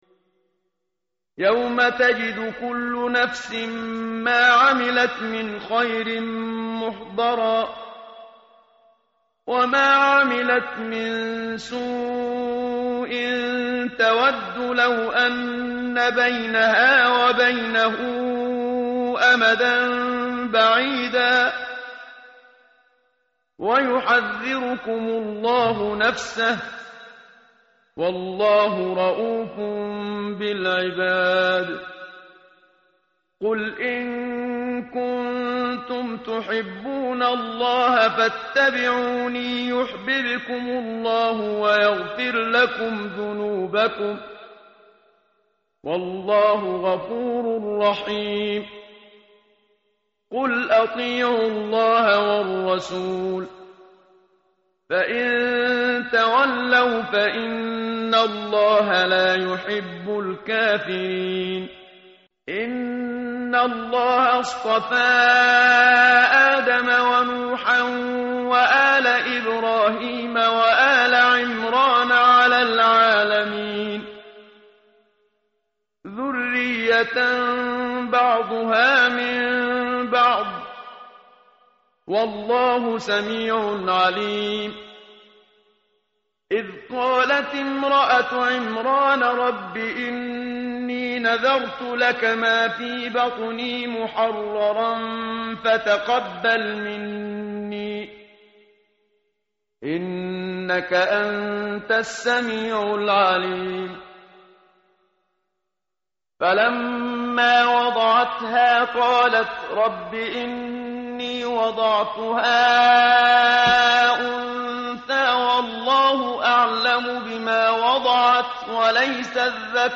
tartil_menshavi_page_054.mp3